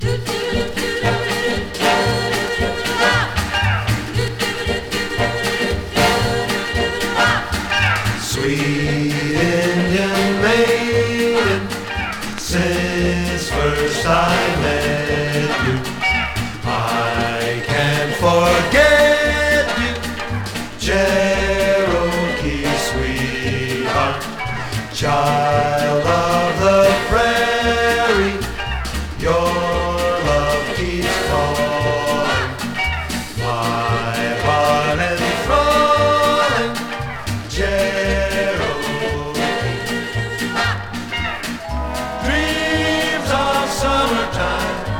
4人組男性ジャズコーラスグループ
彼らのイマジネイティブなボーカルも楽しく、爽快さも有り。
Pop, Vocal　USA　12inchレコード　33rpm　Stereo